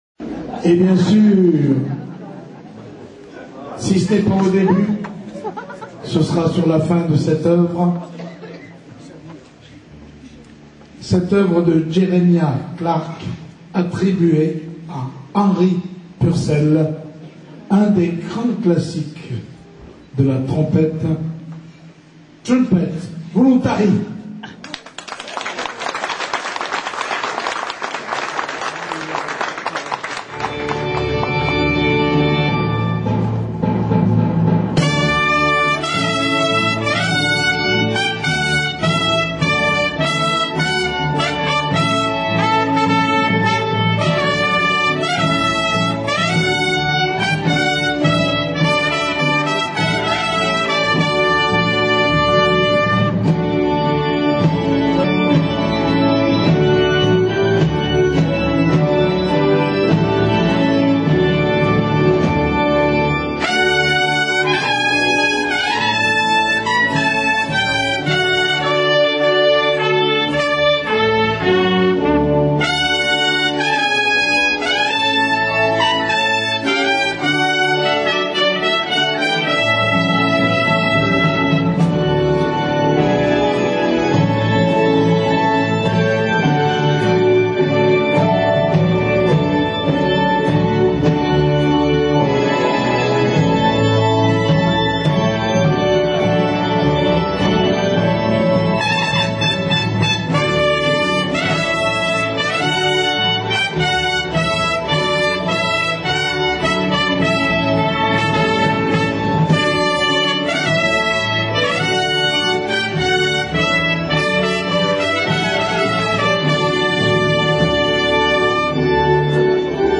Un duo de choc
trumpet voluntary1 .wma